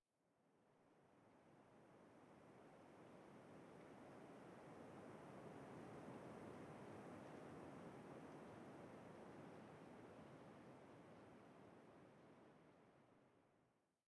Minecraft Version Minecraft Version 1.21.5 Latest Release | Latest Snapshot 1.21.5 / assets / minecraft / sounds / ambient / nether / crimson_forest / particles2.ogg Compare With Compare With Latest Release | Latest Snapshot